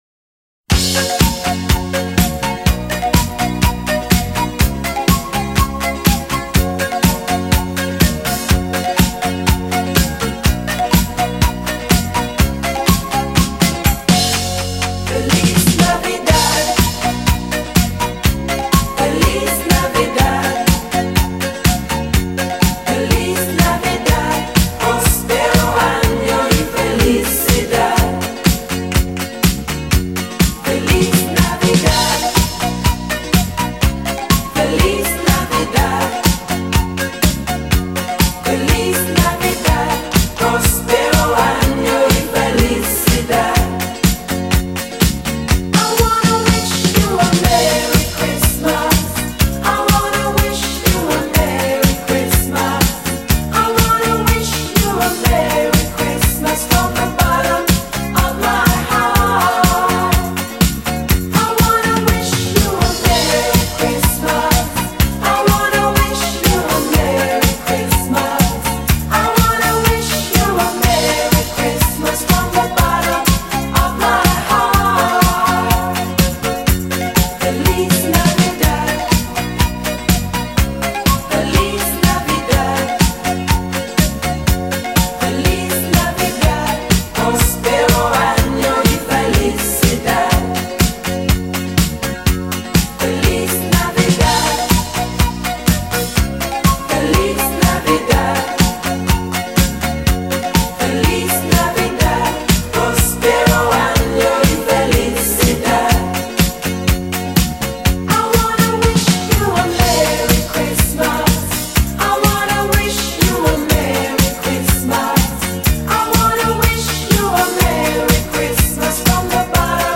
音乐类型： Disco, Pop, Christmas　　 .